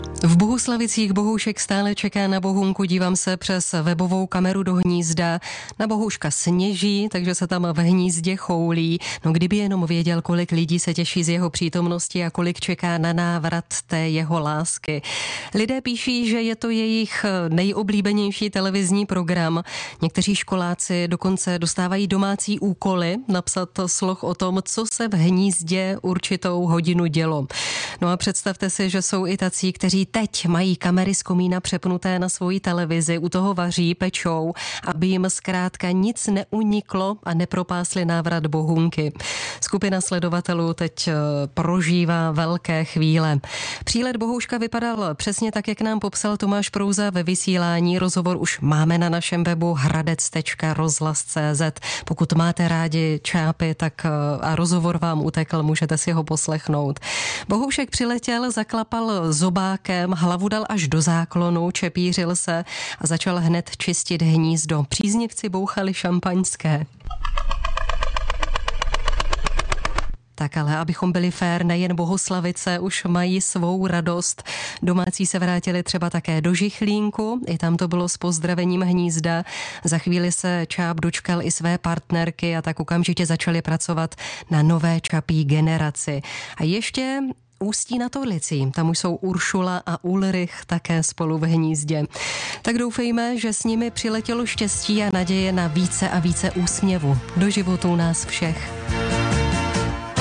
odpoledního vysílání Českého rozhlasu Hradec Králové pronikl další report o aktuálním stavu hnízda, děkujeme zároveň i za polední písničku na přání